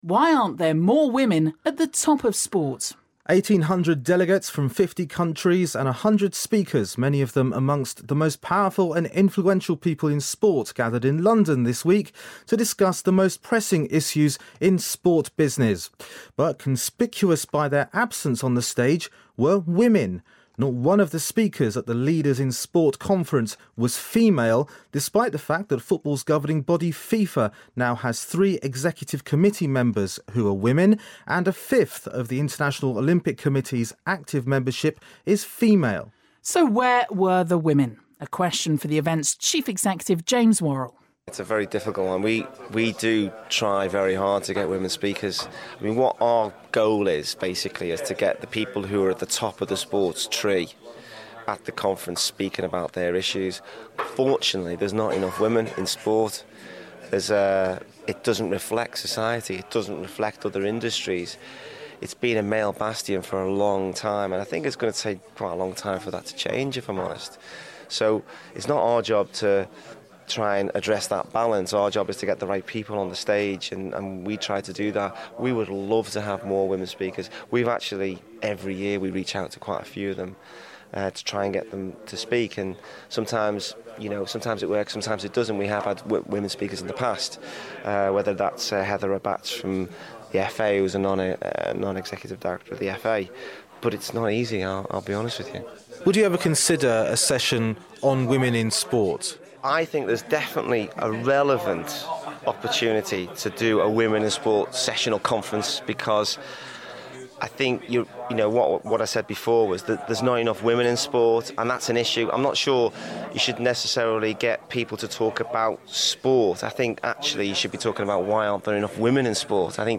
Discussion about the lack of female leaders in sport